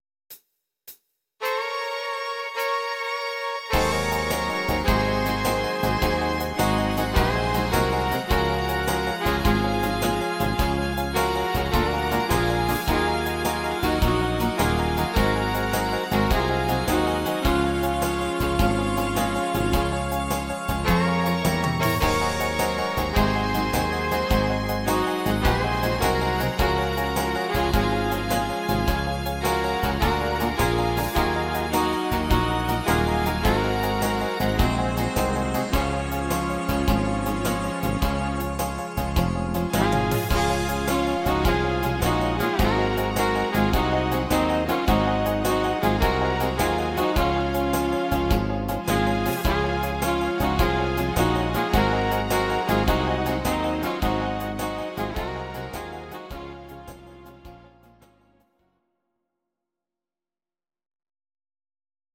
Audio Recordings based on Midi-files
instr. Saxophon